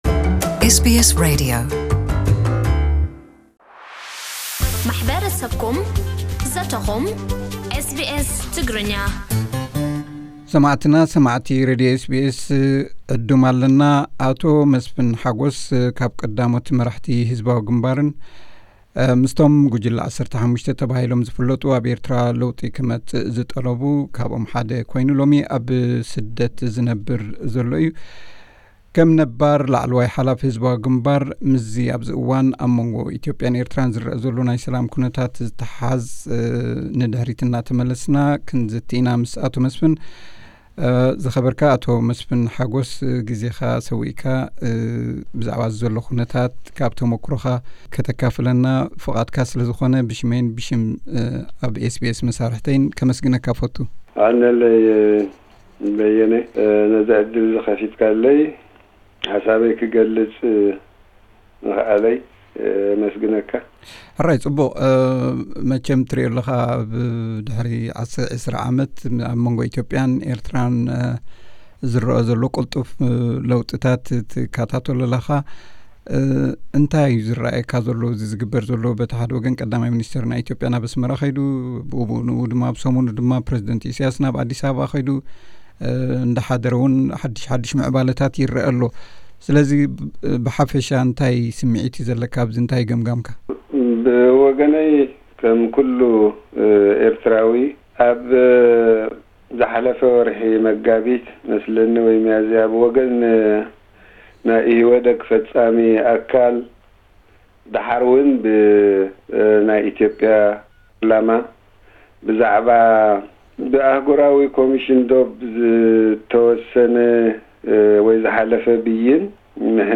ኣቶ መስፍን ሓጎስ ሓደ ካብ ላዕለዎት መራሕቲ ህ.ግ.ሓ.ኤ ዝነበረን ምስቶም ጉጅለ 15 ዝበሃሉ ሰብ-መዚ ኤርትራ ለውጢ ዝጠለቡ ሓደ ኮይኑ፣ ኣብዚ እዋን ኣብ ስደት ዝነብር ዘሎ እዩ። ምስዚ ኣብ ኤርትራን ኢትዮጵያን ዝምዕብል ዘሎ ኩነታት ዝተኣሳሰር ትንታነ ሂቡና ኣሎ።ቀዳማይ ክፋል ናይቲ ቃለ መሕትት ኣብዚ ብምጥዋቕ ክስማዕ ይከኣል እዩ።